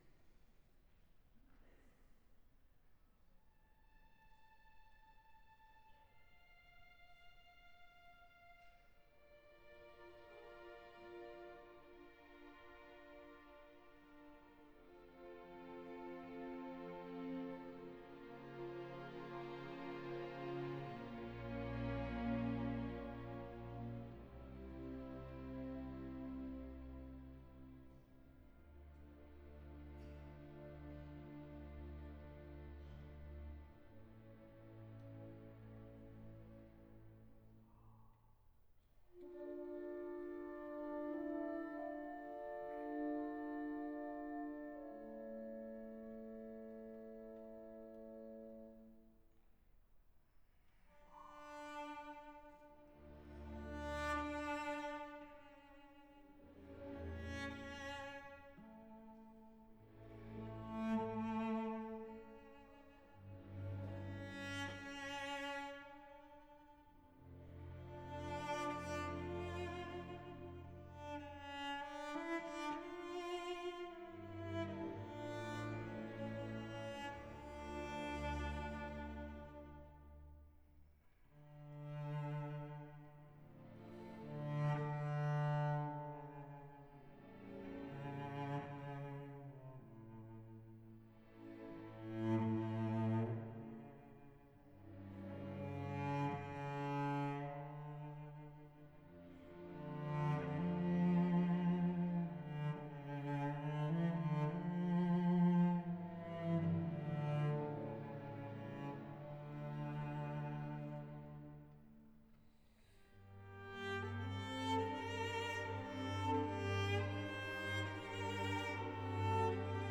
Max Bruch: Kol Nidrei | Euskadiko Orkestra - Basque National Orchestra
Temporada de abono